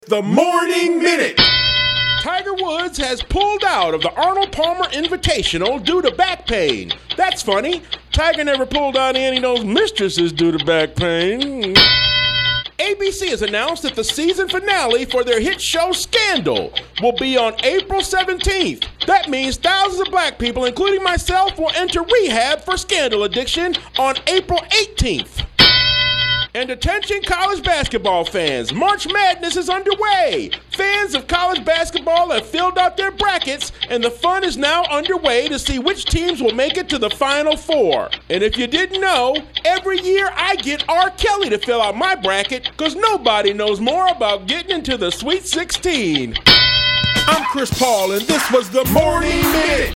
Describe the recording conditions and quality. Live from the Fantastic Voyage Cruise 2014